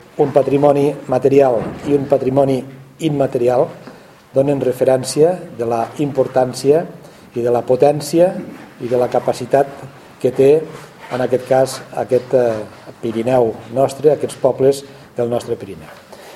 Els representants territorials de les comarques de l’Alta Ribagorça, el Pallars Jussà, el Pallars Sobirà i la Val d’Aran han expressat la seva satisfacció per aquest reconeixement en el transcurs de la roda de premsa que s’ha celebrat aquest dimecres a la Diputació de Lleida.